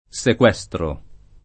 vai all'elenco alfabetico delle voci ingrandisci il carattere 100% rimpicciolisci il carattere stampa invia tramite posta elettronica codividi su Facebook sequestro [ S ek U$S tro ] s. m. — cfr. maestro ; sequestrazione